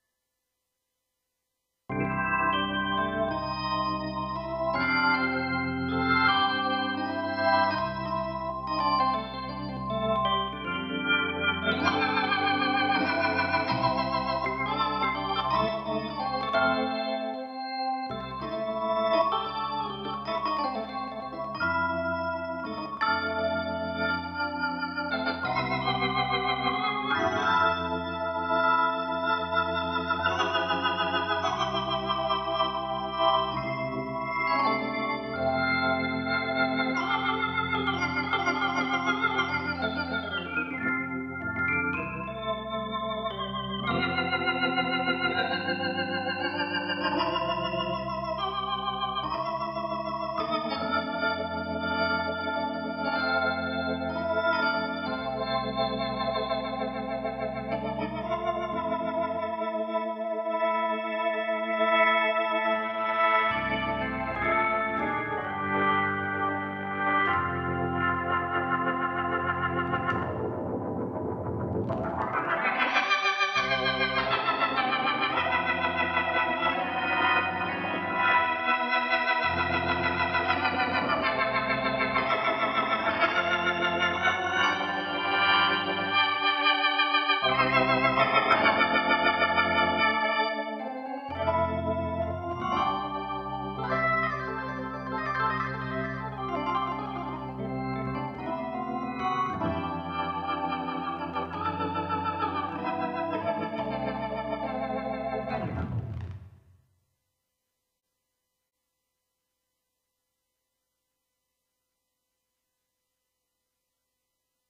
Hammond-PC3-Suonato-da-me-Demo.mp3